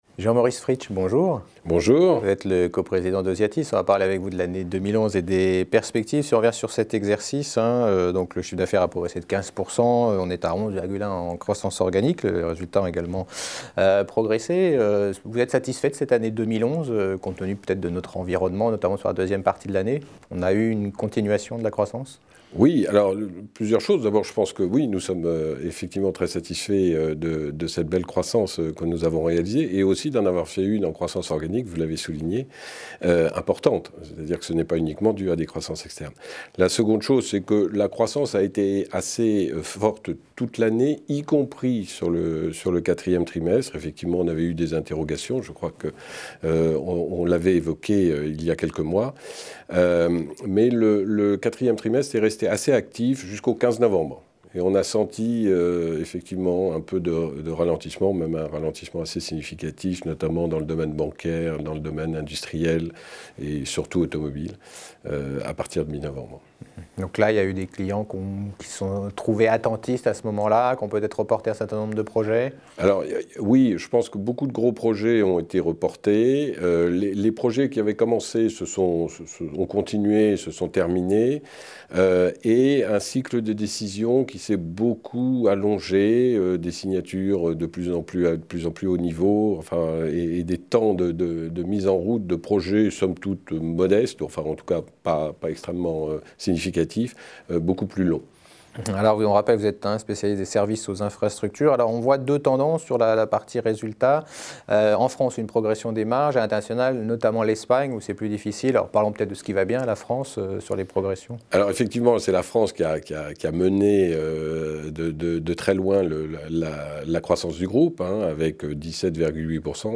Dans cet interview